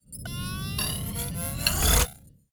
EngineStart.wav